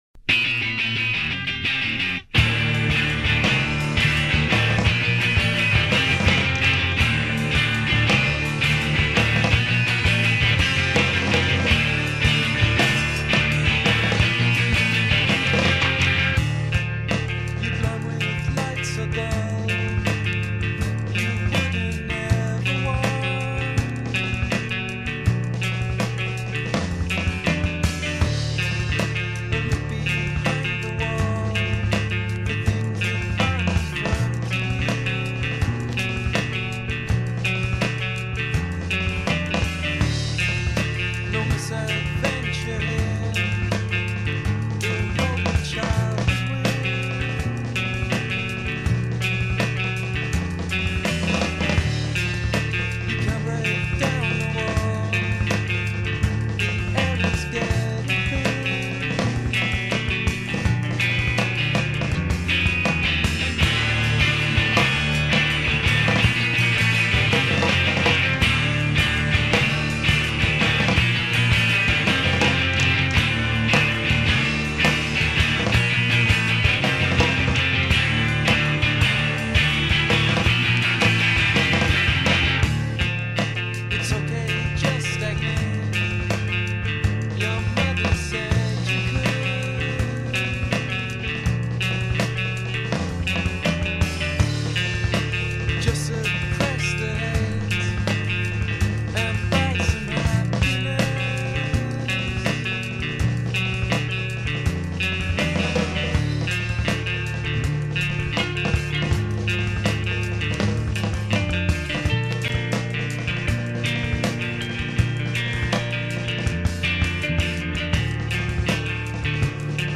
Melancholisch, jazzy, ik dig.